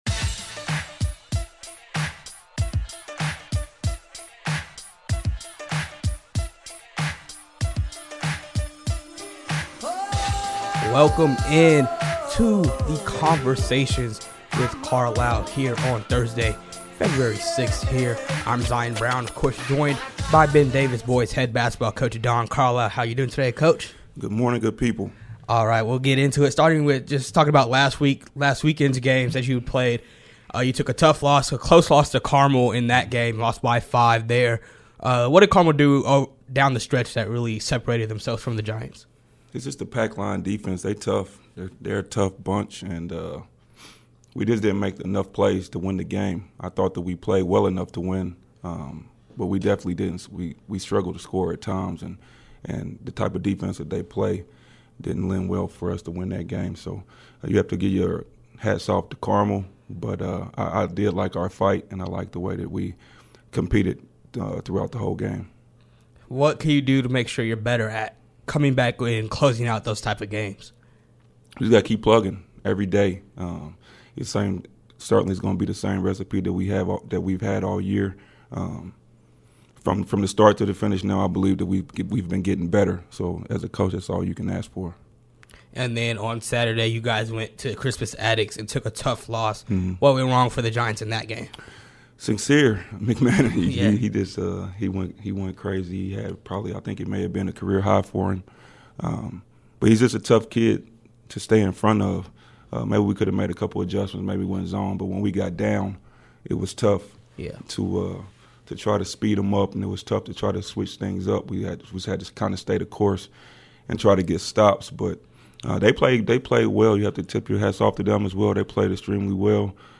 The ninth episode of our weekly conversations